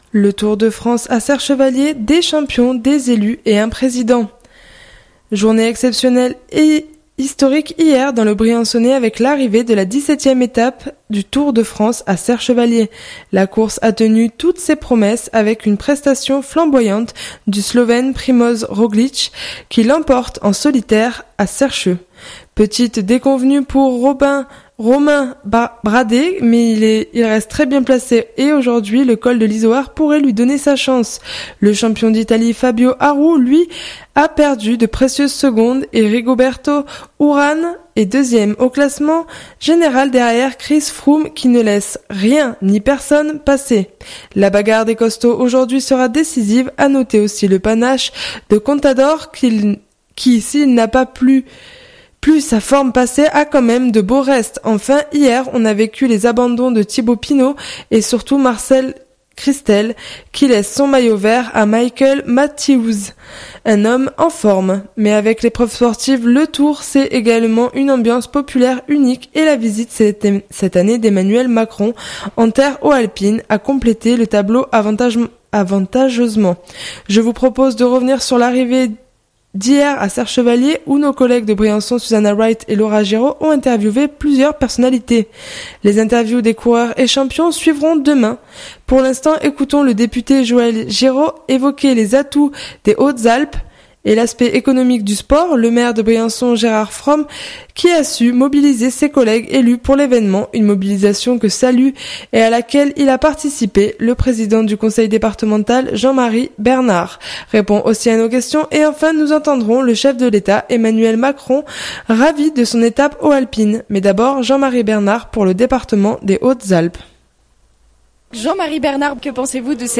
Une mobilisation que salue et à laquelle il a participé, le Président du Conseil départemental Jean-Marie Bernard répond aussi à nos questions et enfin nous entendrons le chef de l’Etat, Emmanuel Macron ravi de son étape haut-alpine.